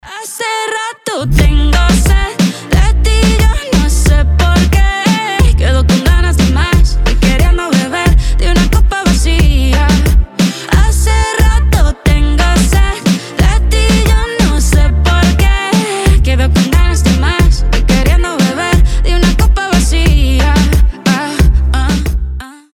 танцевальные , латиноамериканские , испанские